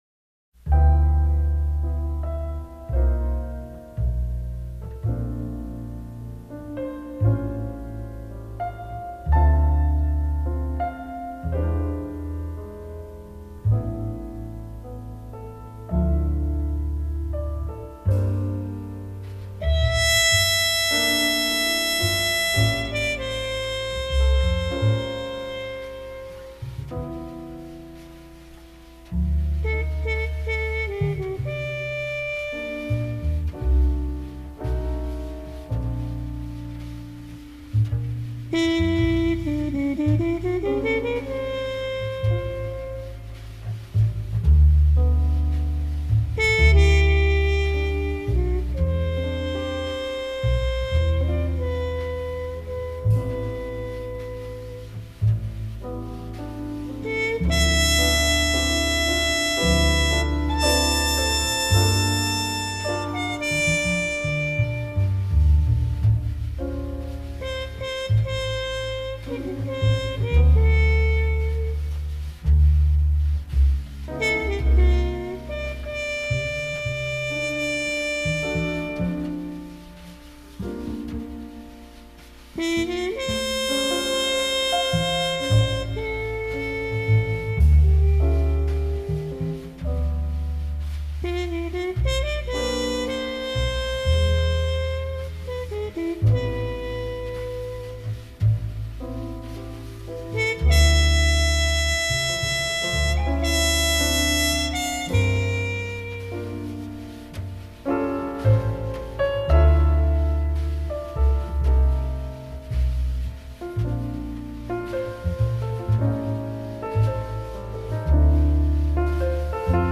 jazz
جاز